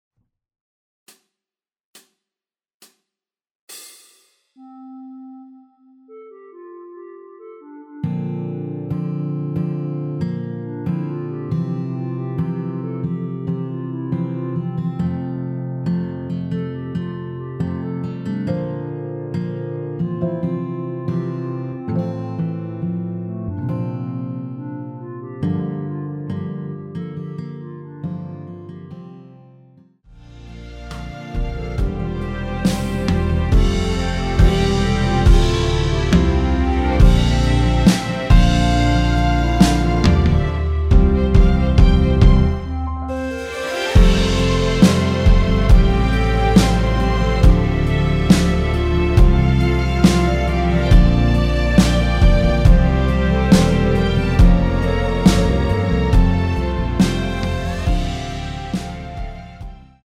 전주 없이 시작하는 곡이라서 시작 카운트 만들어놓았습니다.(미리듣기 확인)
원키에서(-3)내린 멜로디 포함된 MR입니다.
앞부분30초, 뒷부분30초씩 편집해서 올려 드리고 있습니다.
중간에 음이 끈어지고 다시 나오는 이유는